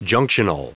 Prononciation du mot junctional en anglais (fichier audio)
Prononciation du mot : junctional